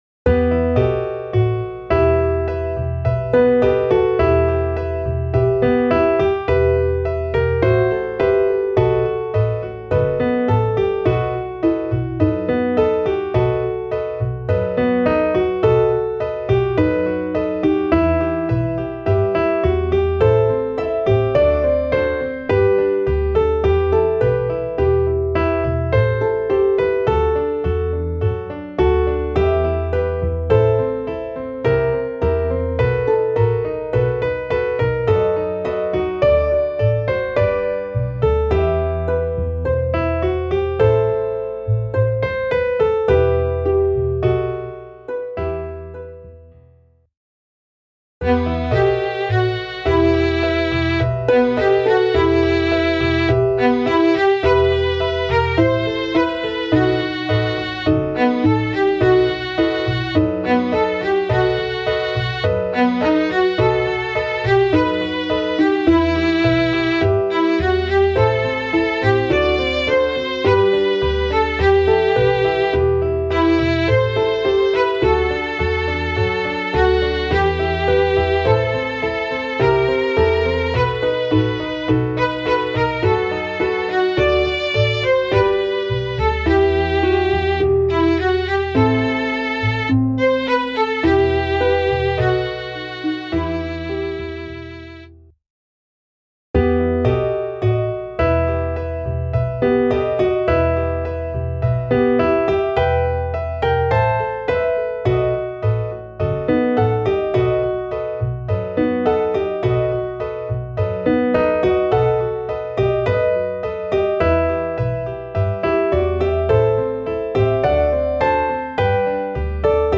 midi_im-feuerkreis_klavier_320.mp3